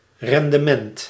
Ääntäminen
Ääntäminen : IPA : /pər.ˈfɔr.məns/ US : IPA : [pər.ˈfɔr.məns] UK : IPA : [pə.ˈfɔː.məns] US : IPA : [pɚ.ˈfɔɹ.məns] Tuntematon aksentti: IPA : /pər.ˈfɔr.mənts/ IPA : /pə.ˈfɔr.mənts/ IPA : /pə.ˈfɔr.məns/